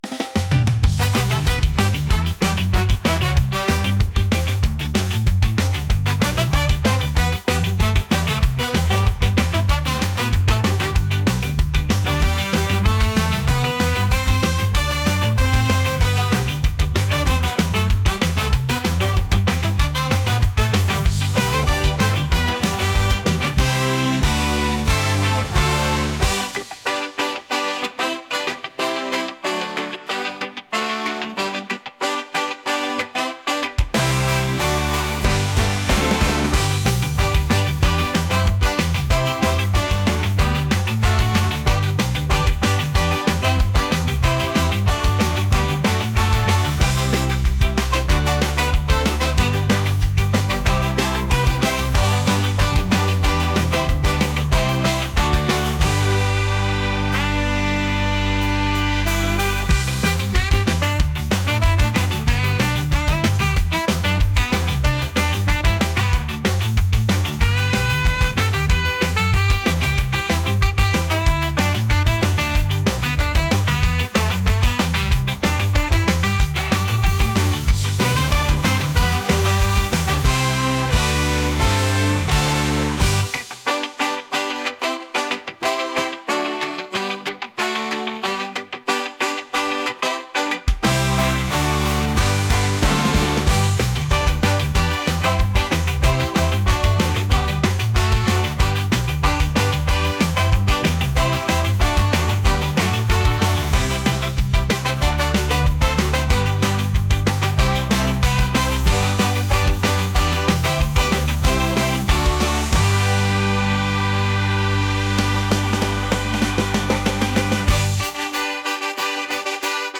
reggae | ska